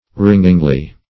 ringingly - definition of ringingly - synonyms, pronunciation, spelling from Free Dictionary Search Result for " ringingly" : The Collaborative International Dictionary of English v.0.48: Ringingly \Ring"ing*ly\, adv.
ringingly.mp3